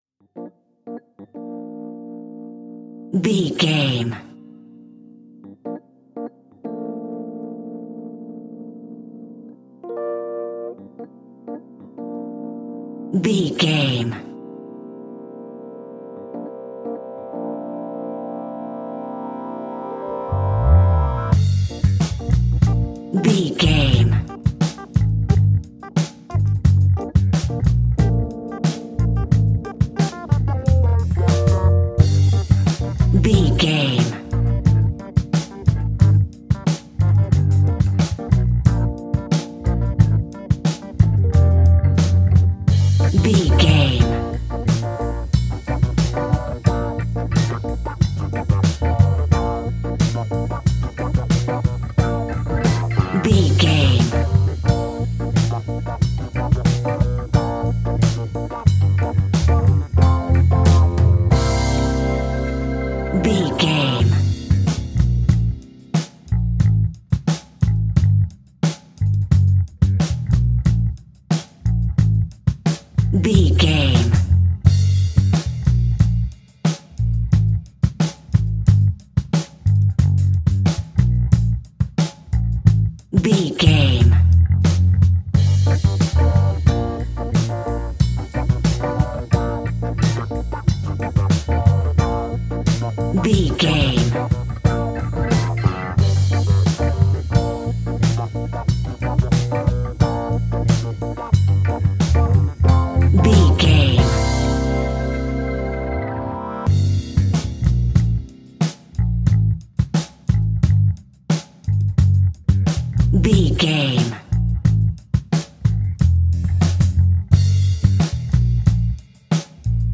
Aeolian/Minor
funky
uplifting
bass guitar
electric guitar
organ
drums
saxophone